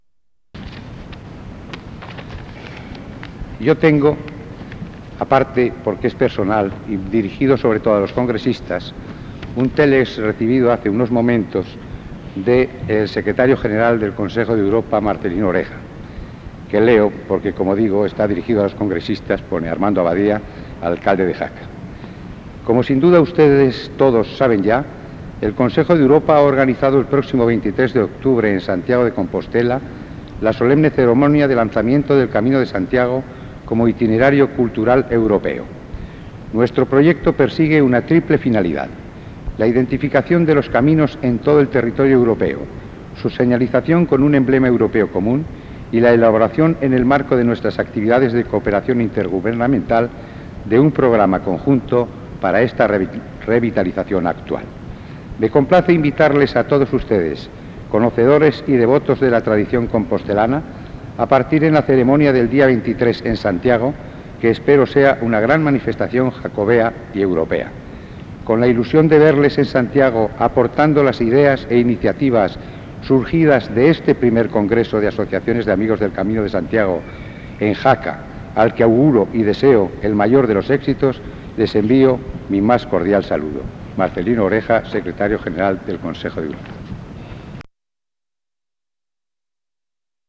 I Congreso Internacional de Jaca. Saludo de las Autoridades. Miércoles 23 de septiembre, S.I. Catedral de Jaca, 1987